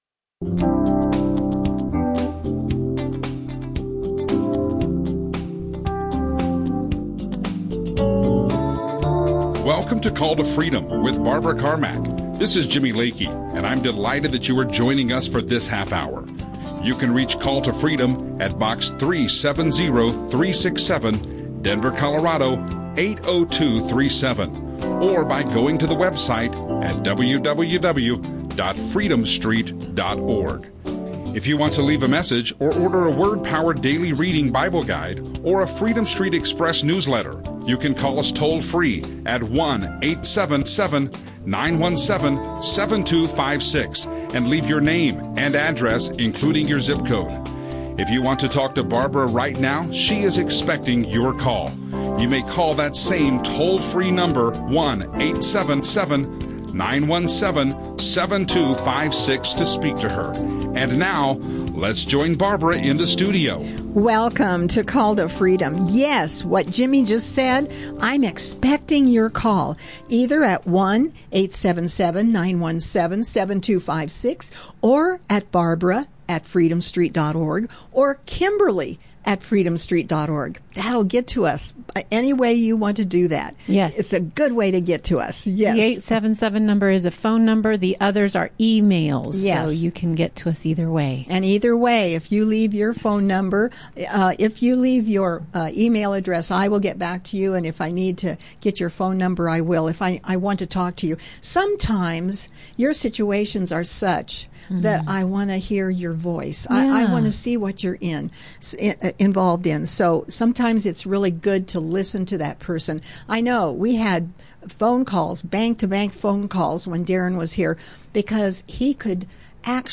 call in show
Christian talk
radio show